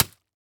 horror
Flesh Hits Wet Bloody Ground